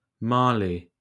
Ääntäminen
IPA : /ˈmɑːli/